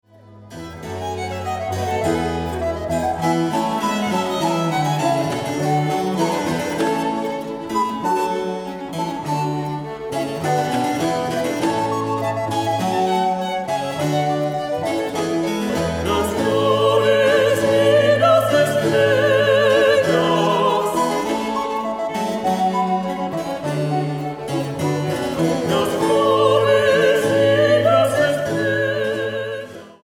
Versiones barroco americano